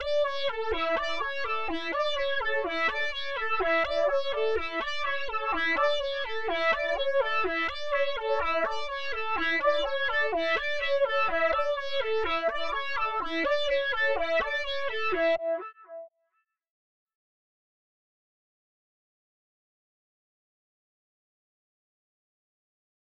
hypnoarp125bpm.ogg